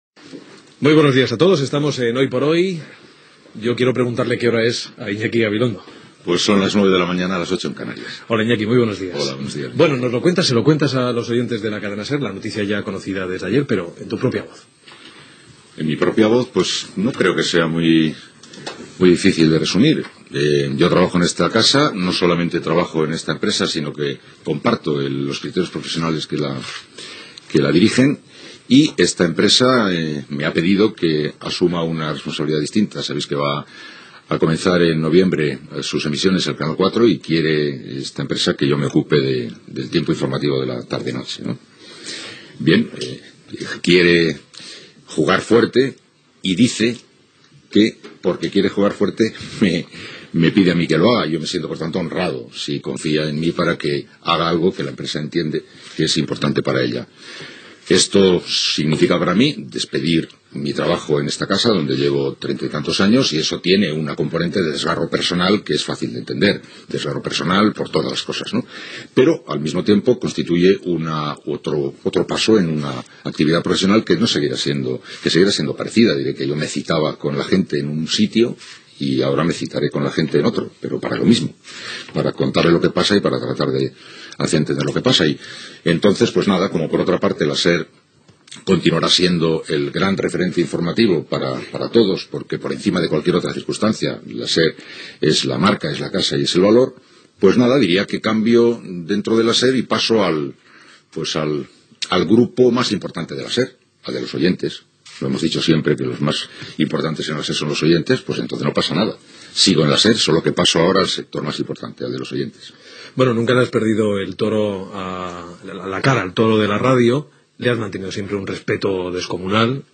Identificació del programa, hora. Iñaki Gabilondo comunica que deixarà de presentar "Hoy por hoy" per passar a presentar un informatiu televisiu a Cuatro, propietat del Grupo Prisa, el mes de novembre d'aquell any
Info-entreteniment